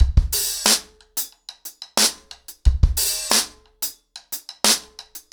ROOTS-90BPM.33.wav